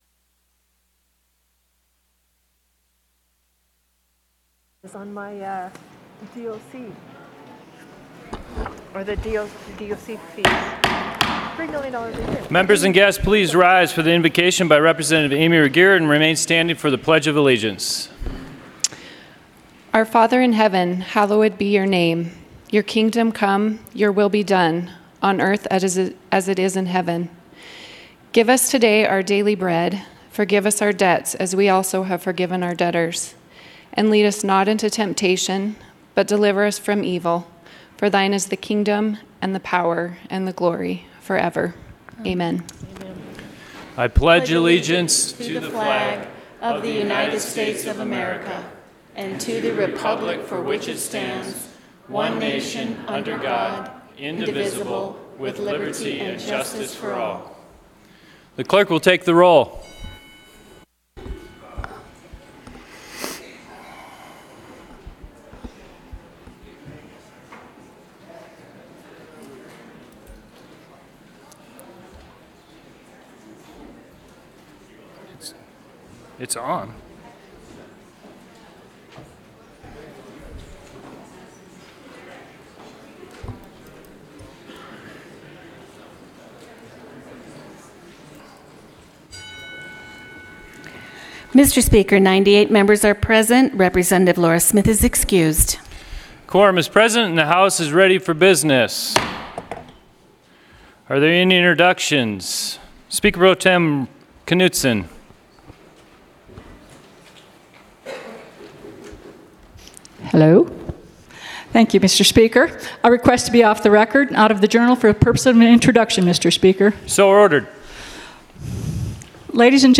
House Floor Session